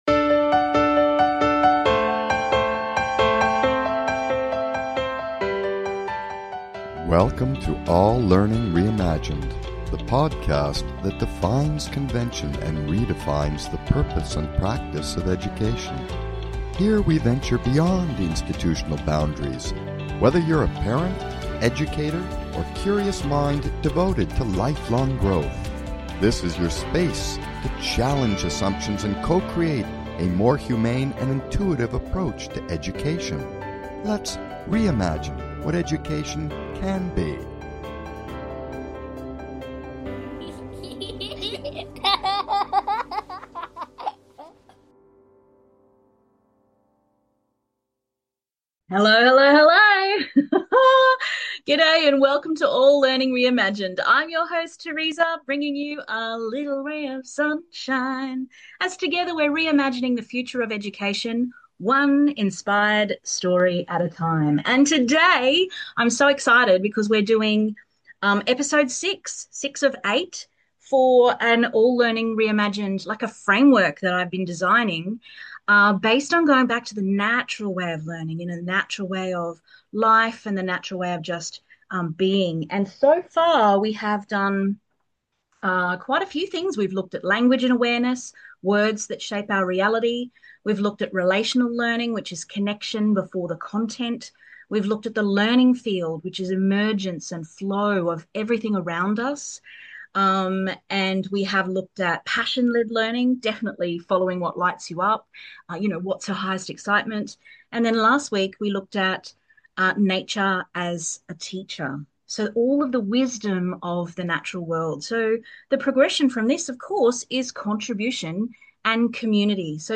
Talk Show Episode, Audio Podcast, All Learning Reimagined and Contribution and Community on , show guests , about Contribution and Community,Learning Through Giving,Reimagining Education,Community Contribution,The Psychology of Purpose and Wellbeing,Learning Paradigm Shift,Philosophical Shift,From Get to Give,from passive consumption to active,heart-centered contribution, categorized as Education,Health & Lifestyle,Kids & Family,Philosophy,Psychology,Science,Self Help,Society and Culture,Technology